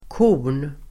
Uttal: [ko:r_n]